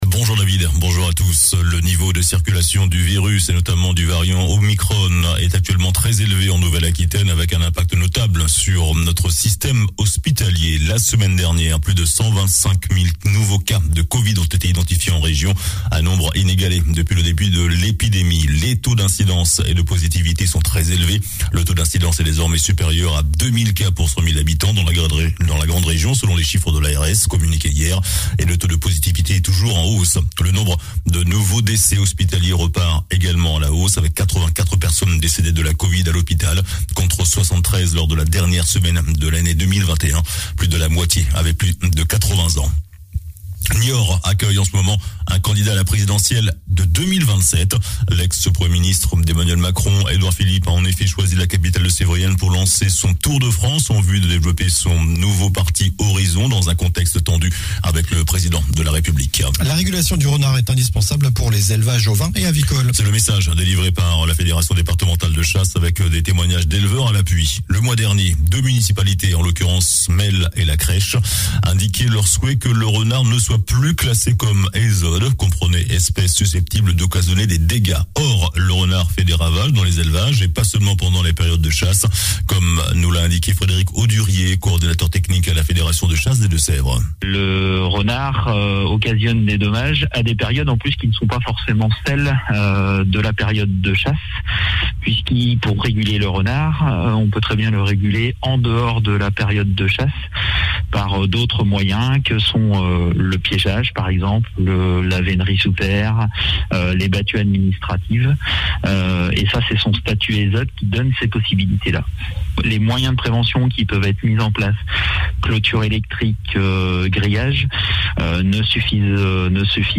JOURNAL DU SAMEDI 15 JANVIER